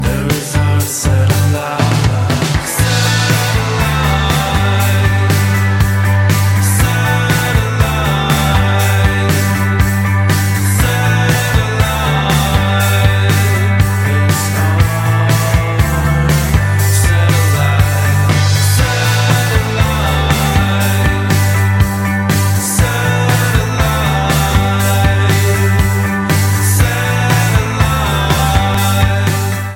• Качество: 192, Stereo
Приятная мелодичная песня в стиле инди-рок